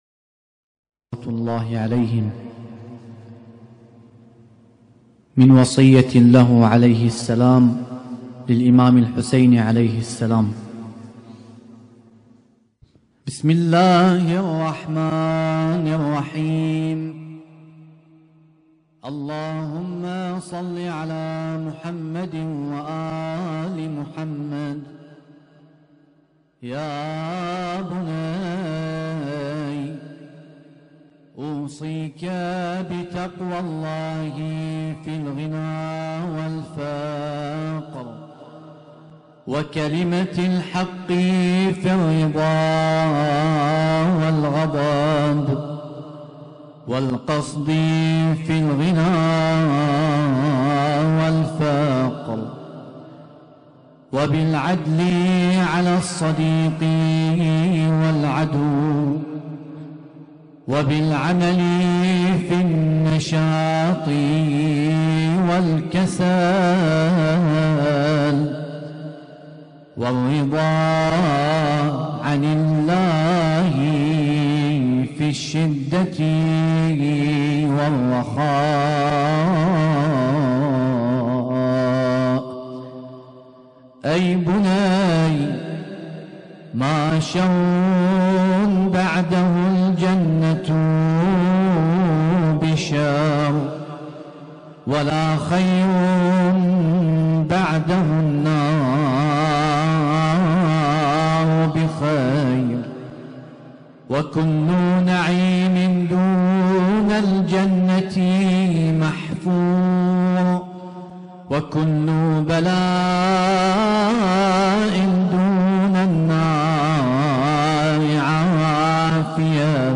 Husainyt Alnoor Rumaithiya Kuwait
ليلة (4) من شهر محرم الحرام 1447هـــ